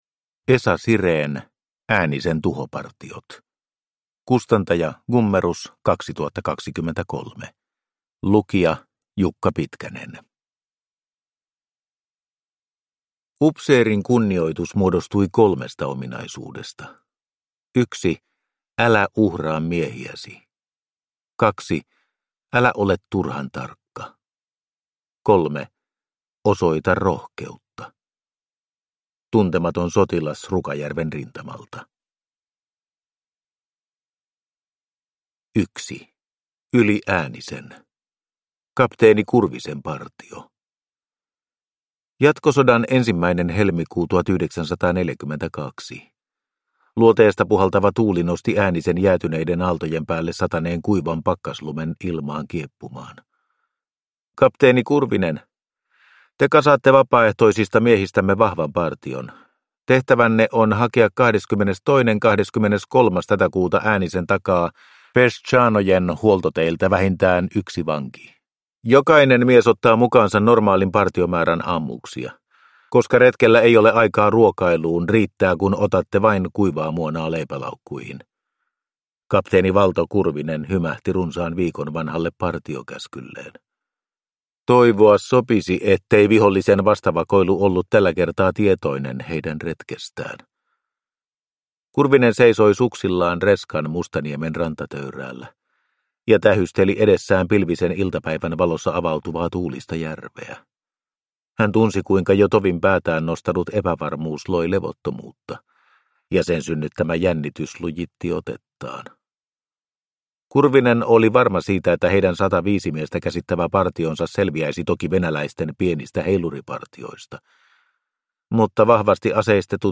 Äänisen tuhopartiot – Ljudbok